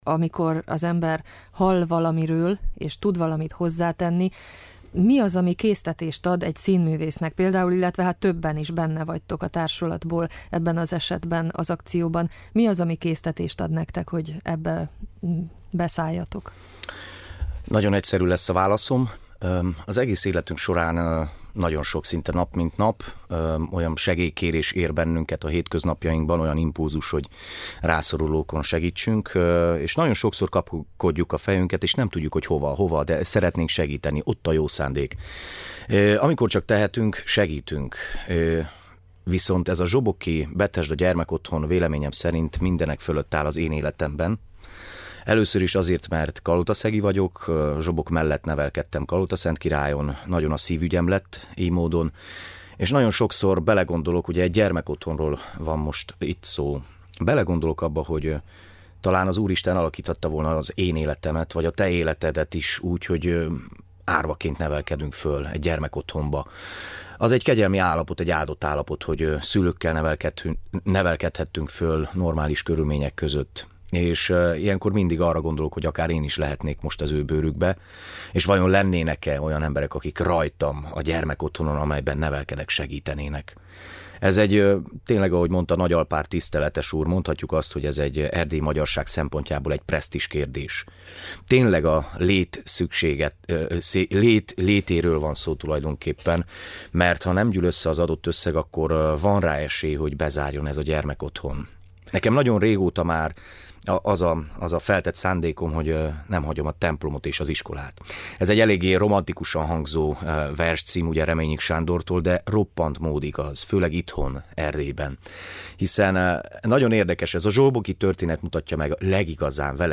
a Hangoló vendége.